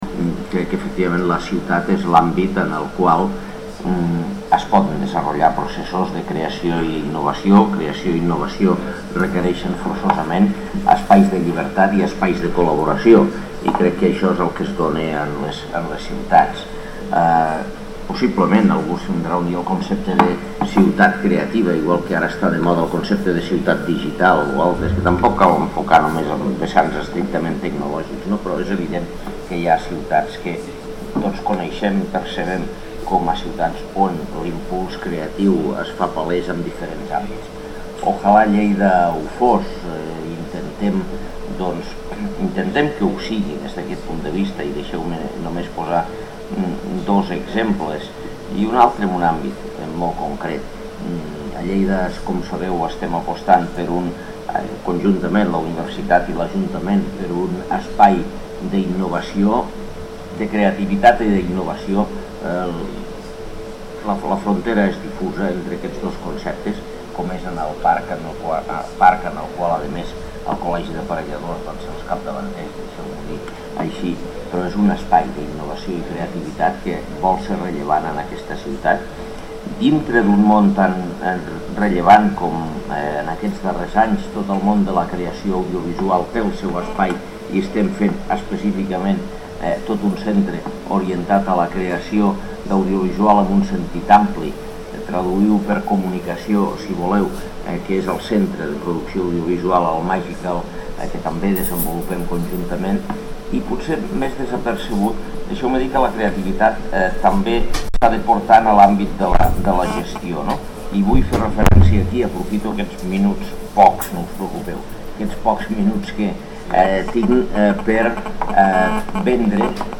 L'alcalde de Lleida intervé en la inauguració de la X Setmana d’Estudis Urbans destacant la importància de la innovació en les ciutats i remarcant els projectes que estan en marxa a Lleida: Magical i GLOBALleida